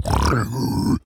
Minecraft Version Minecraft Version 25w18a Latest Release | Latest Snapshot 25w18a / assets / minecraft / sounds / mob / piglin_brute / angry3.ogg Compare With Compare With Latest Release | Latest Snapshot
angry3.ogg